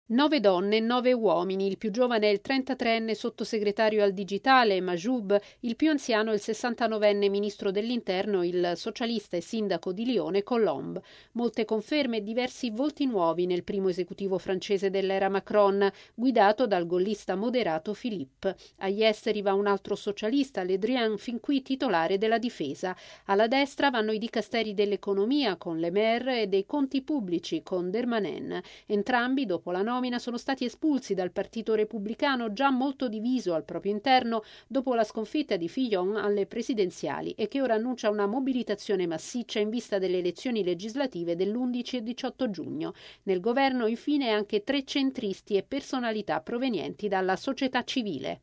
Dopo due giorni di consultazioni, la Francia ha un nuovo governo. Il presidente Emmanuel Macron ha messo a punto l’esecutivo guidato dal premier Edouard Philippe. Il servizio